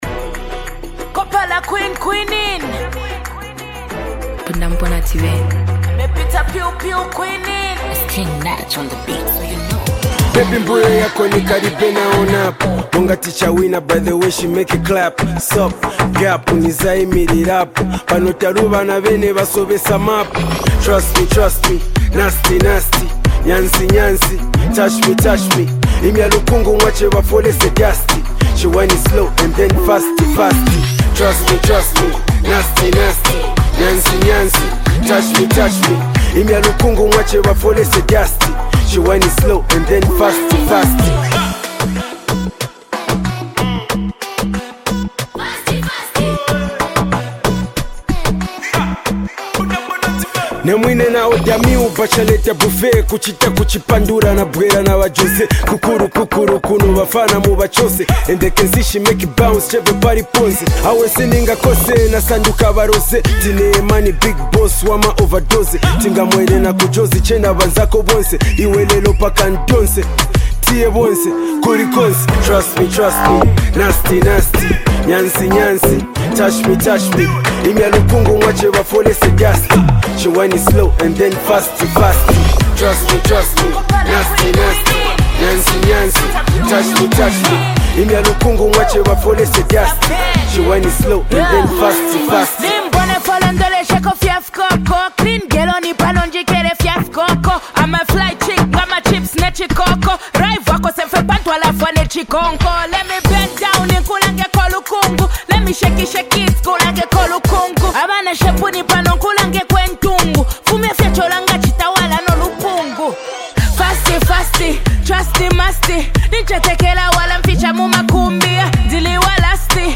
a bold, energetic song